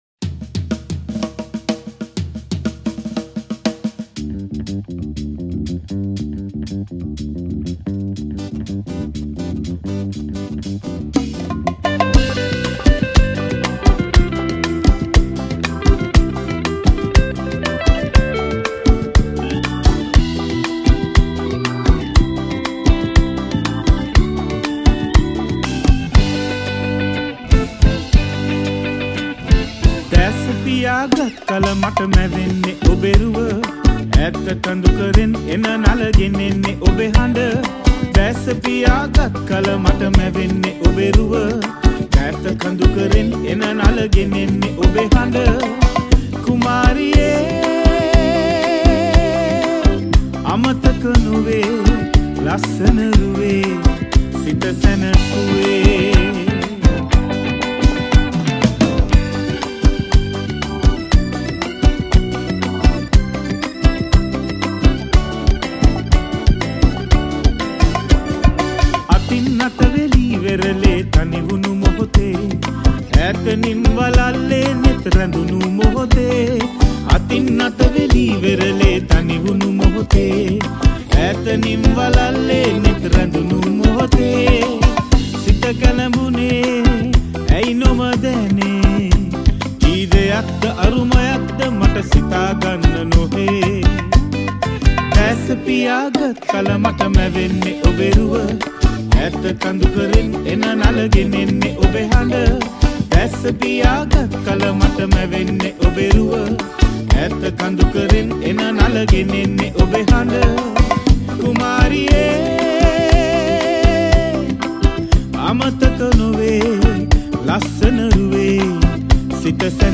Covers